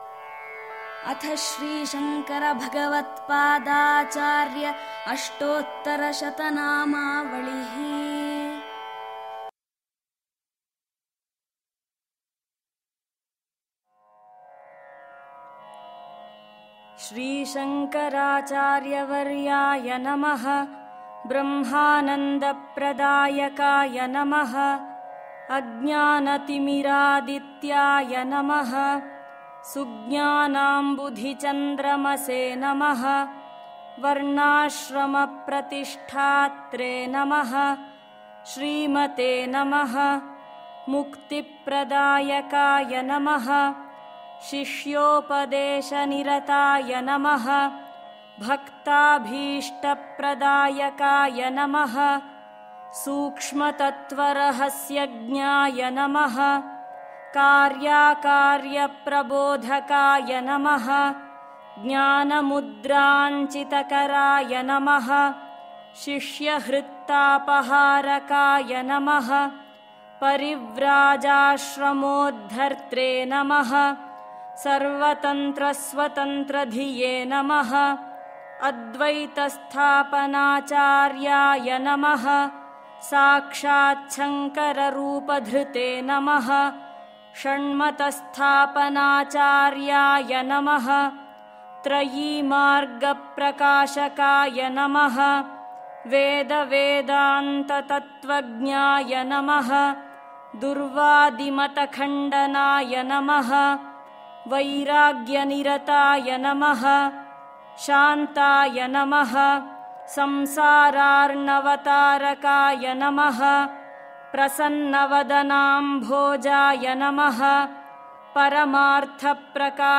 Parayana Audio as per the order